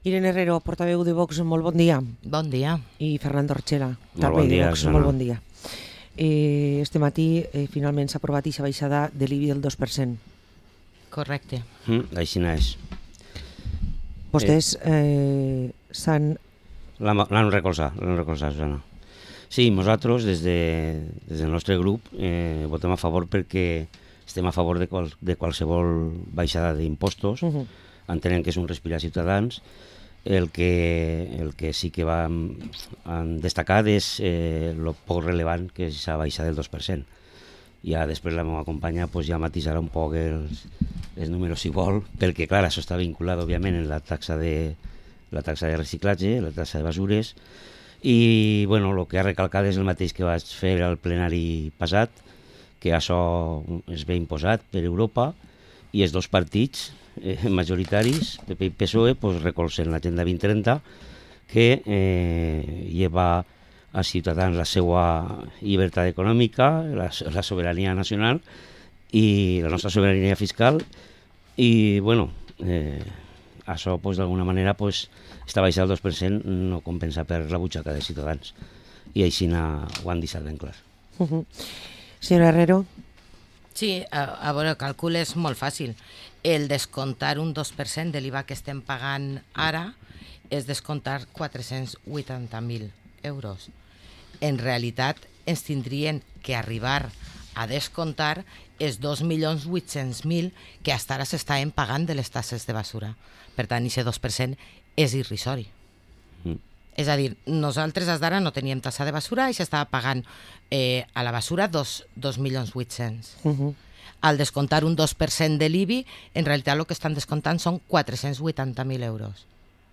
Parlem amb Irene Herrero i Fernando Archela de VOX, reguidors a l´Ajuntament de Vila-real